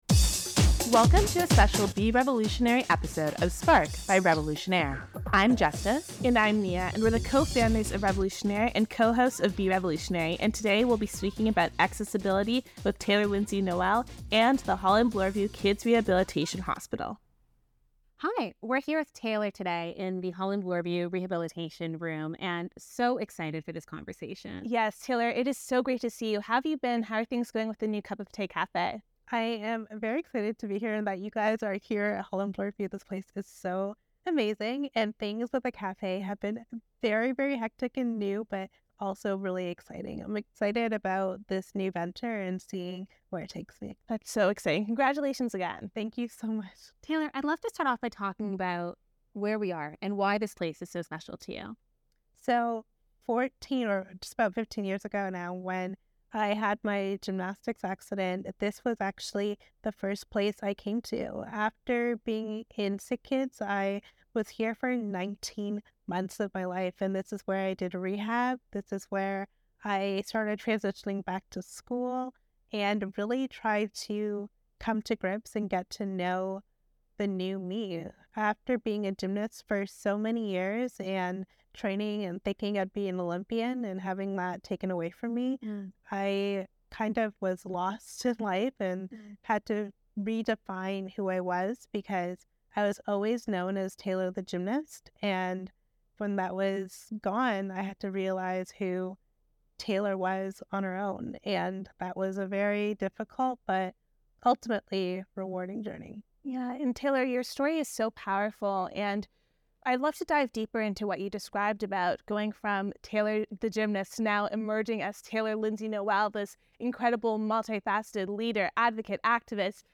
The three have a conversation about the importance of inclusivity and accessibility as well as the work of Holland Bloorview Kids Rehabilitation Centre.